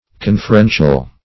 Conferential \Con`fer*en"tial\